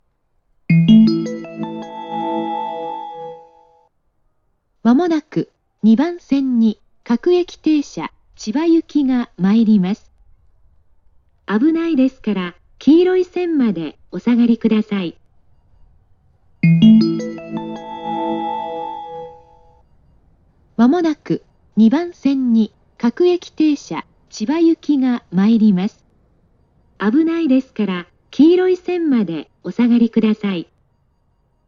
発車メロディー
●音質：良